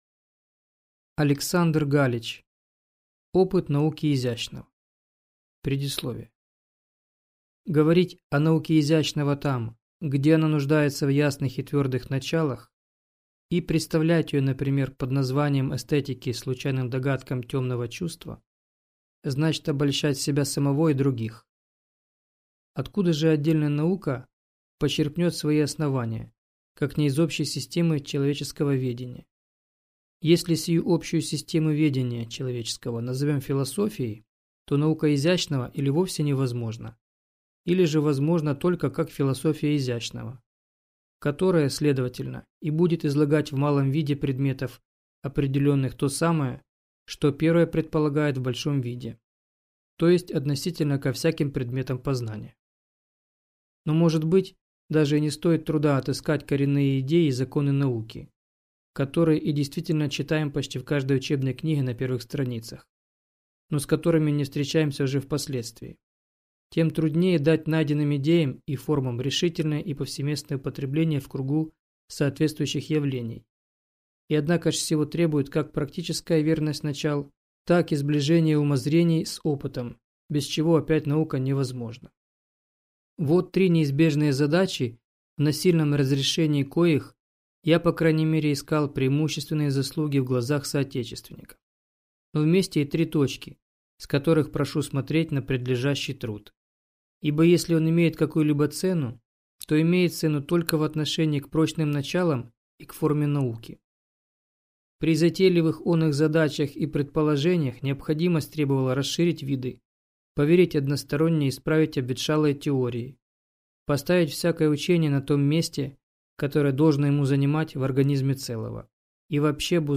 Аудиокнига Опыт науки изящного | Библиотека аудиокниг